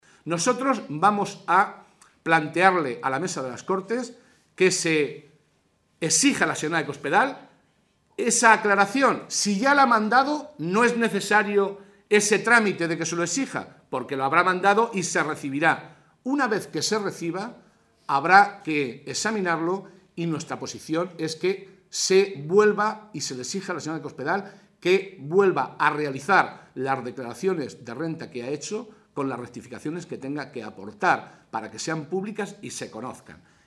José Molina, portavoz del Grupo Parlamentario Socialista
Cortes de audio de la rueda de prensa